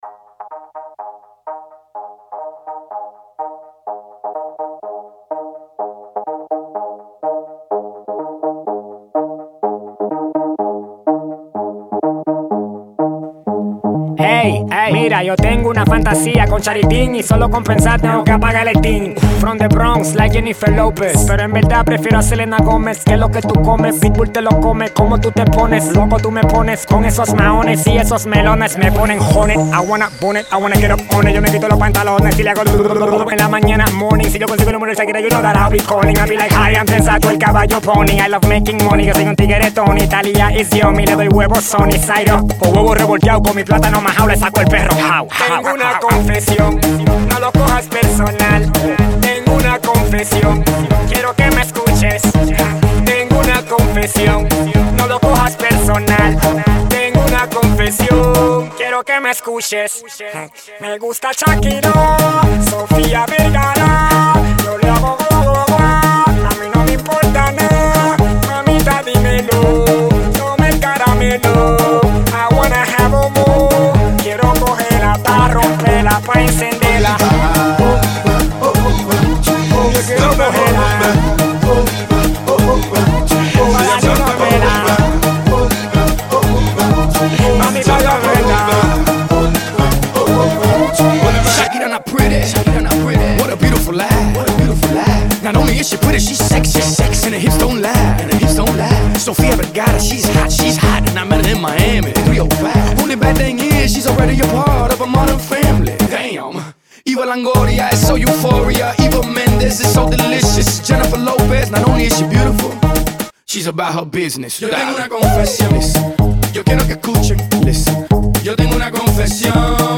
latino version